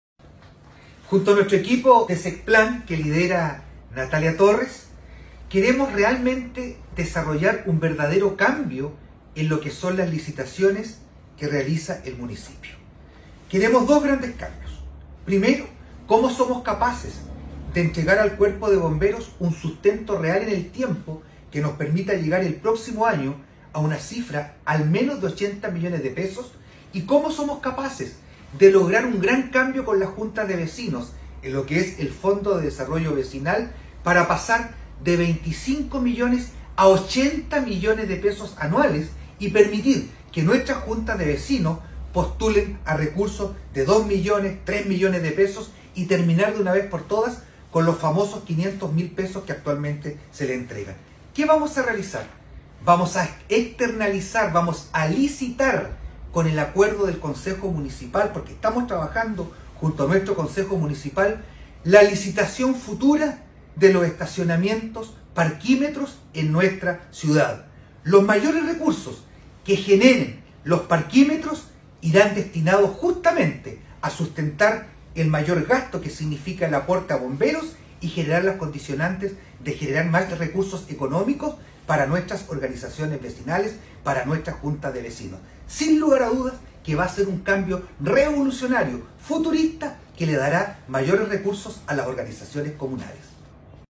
ALCALDE-VERA-MAS-RECURSOS.mp3